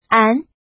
怎么读
án àn